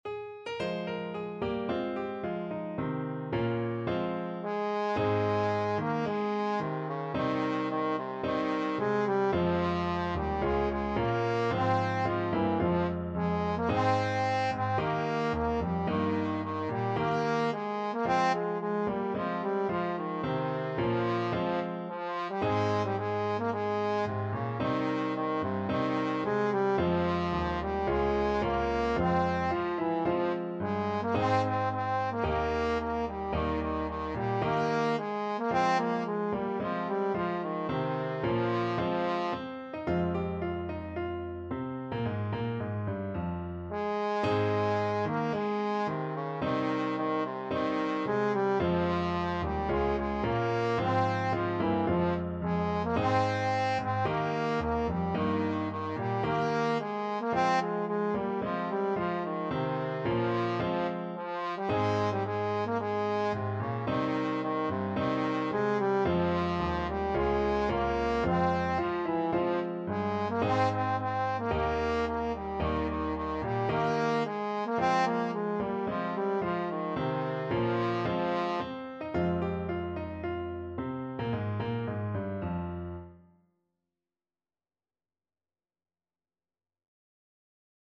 ~ = 110 Allegro (View more music marked Allegro)
4/4 (View more 4/4 Music)
Traditional (View more Traditional Trombone Music)